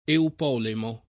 Eupolemo [ eup 0 lemo ]